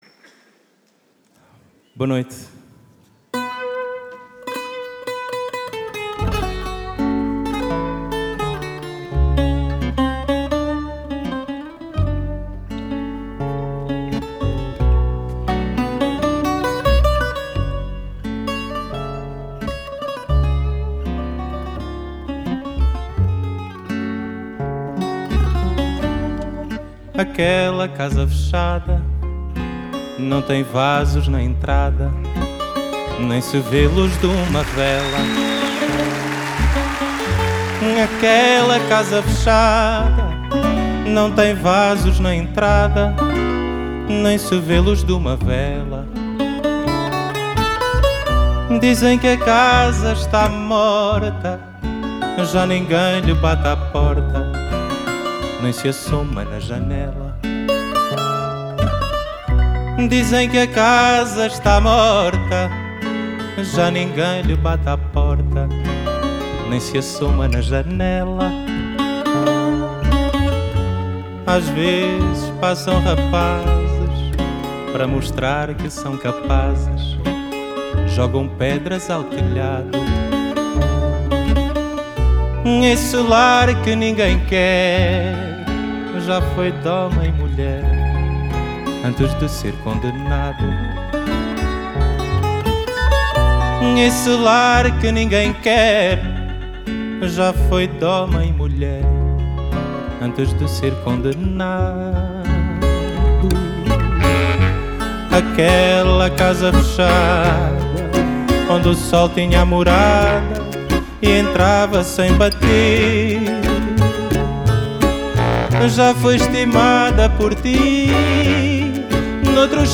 Genre: Fado, Fado Bossa Nova, Folk, World